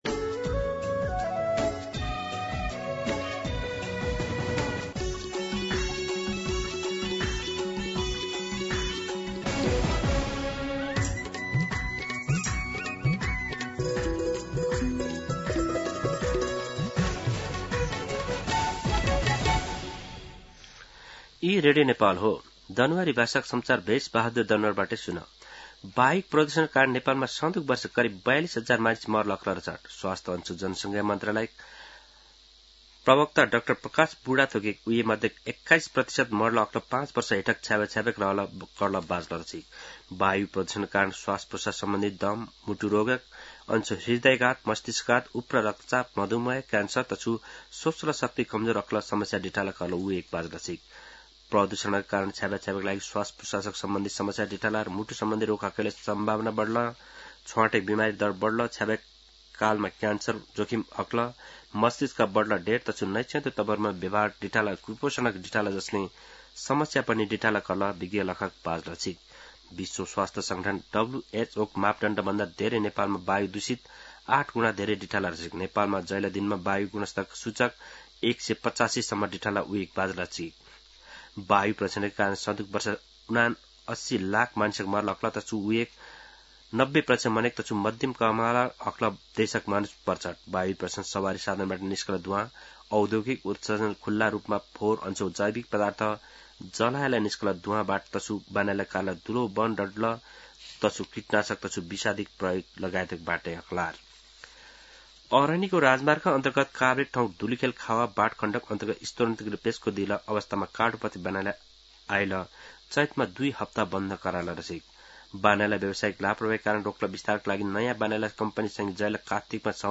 दनुवार भाषामा समाचार : ३० फागुन , २०८२